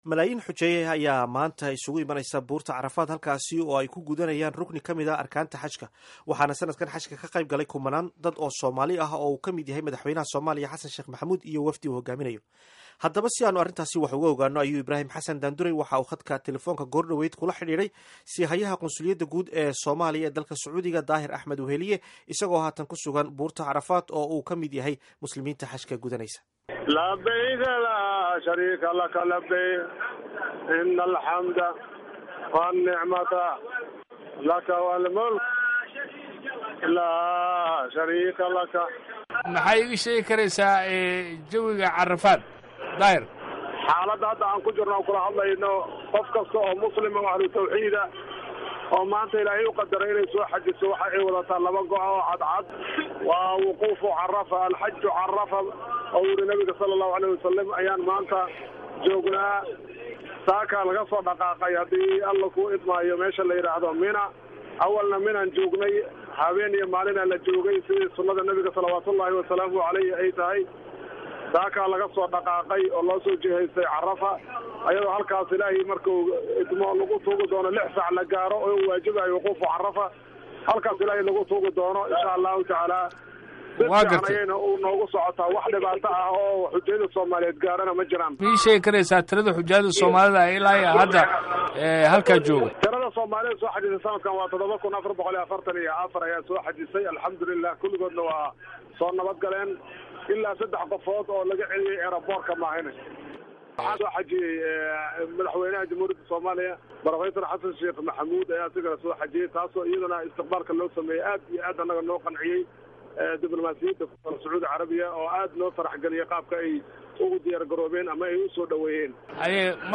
Dhegayso: Waraysi ku saabsan Xajka iyo Carafo